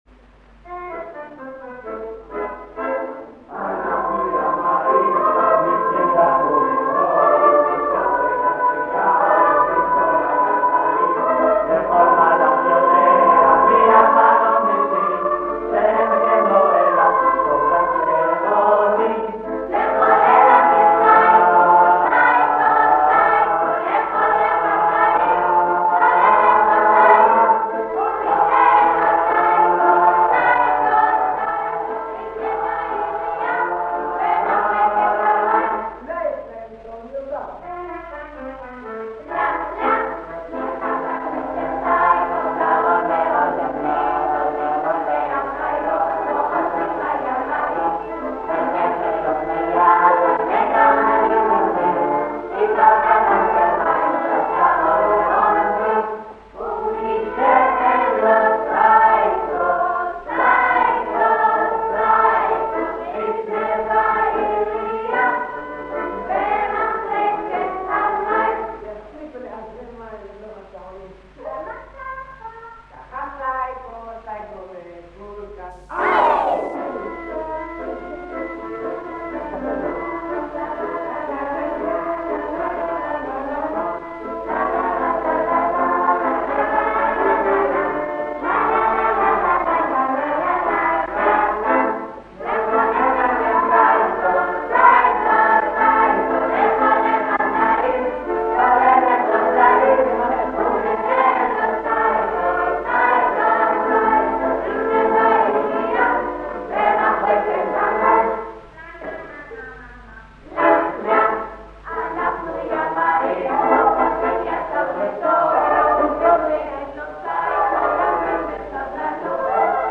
ההקלטות, על סלילים שנשמרו 40 שנה, נשמעות קצת עתיקות, אבל אי אפשר לפספס את הקולות, האקורדיאון והראשוניות!